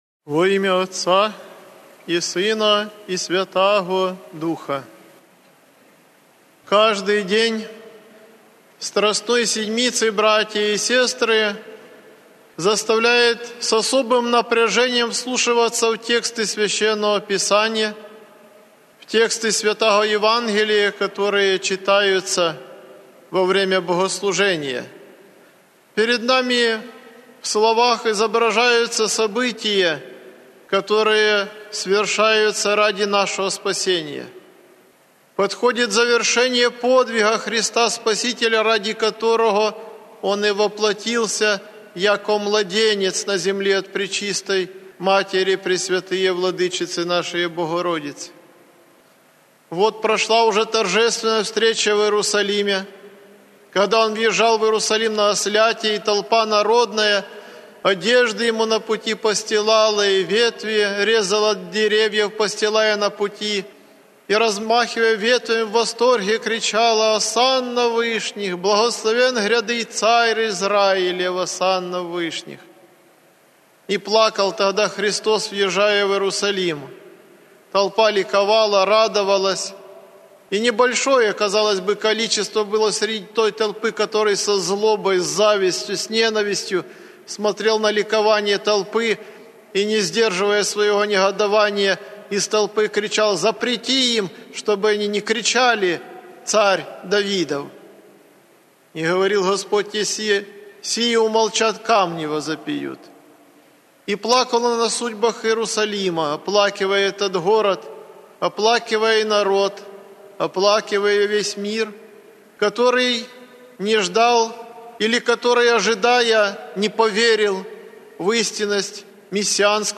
На данной странице собраны проповеди 2017 г. Митрополита Арсения.
Проповеди 2017 г.